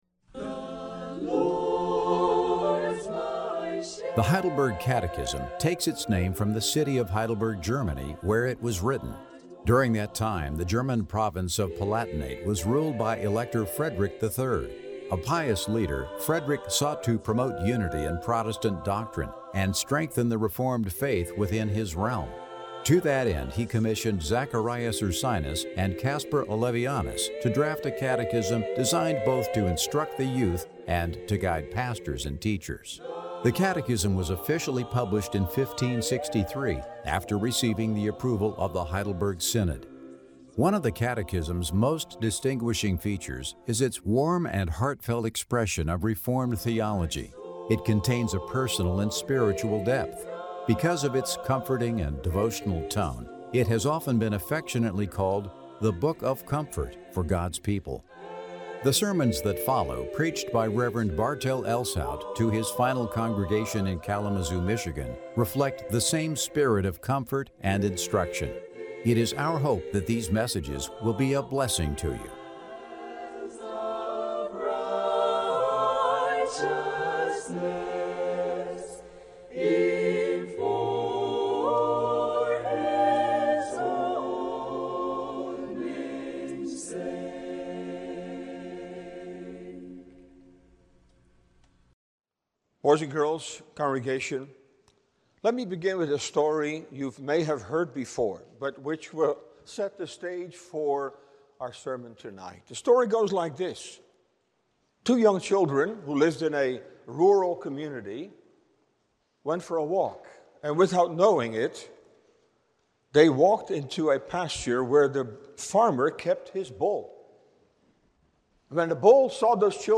Sermon Downloads